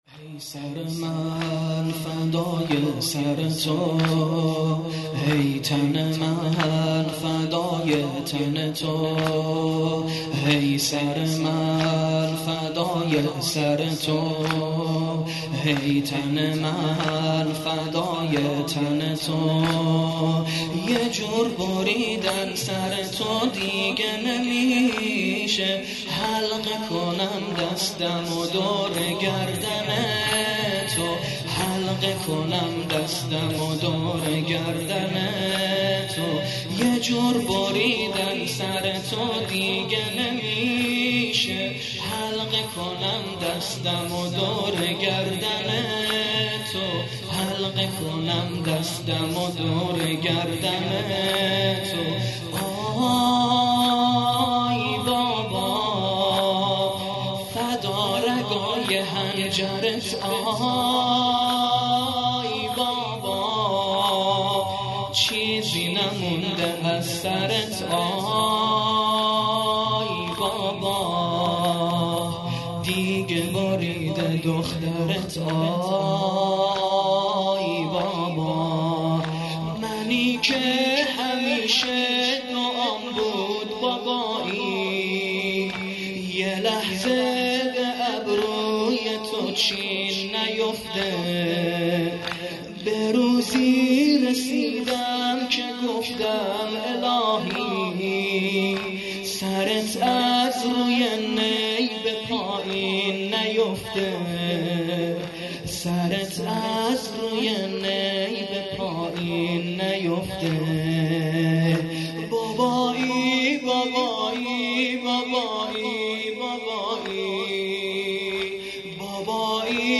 محرم الحرام 1442